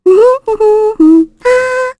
Mirianne-vox-Hum_kr.wav